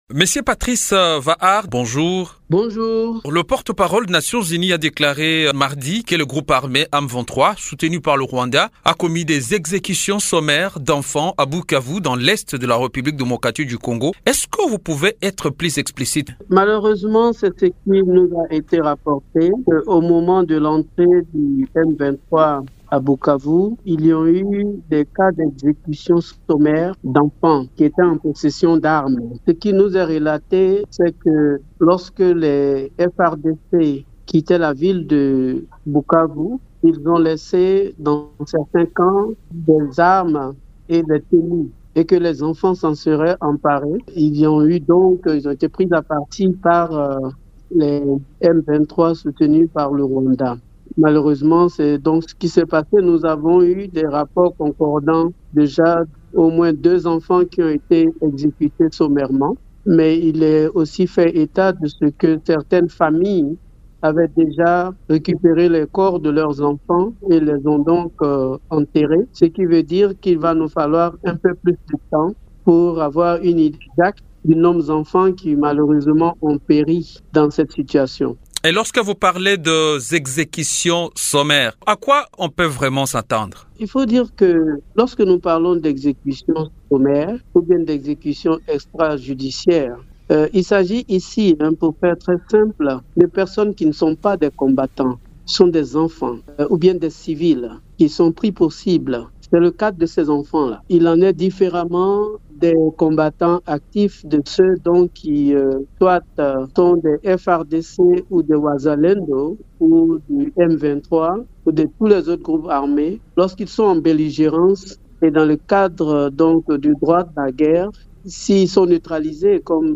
Il répond aux questions de